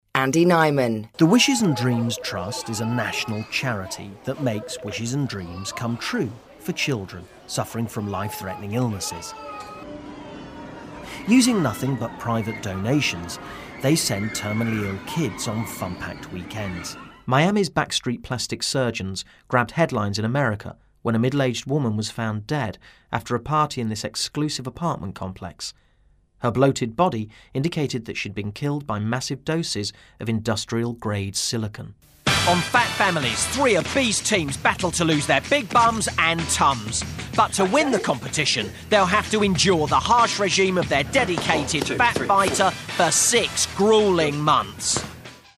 Voice Reel
Andy Nyman - Documentary Reel
Andy Nyman- Doco reel.mp3